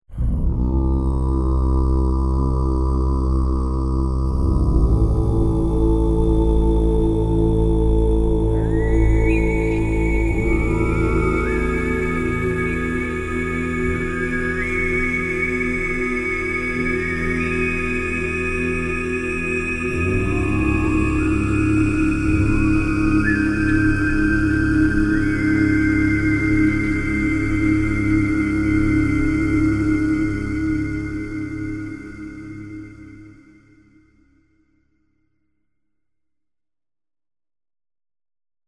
Tuvan Drones demo =5-B02.mp3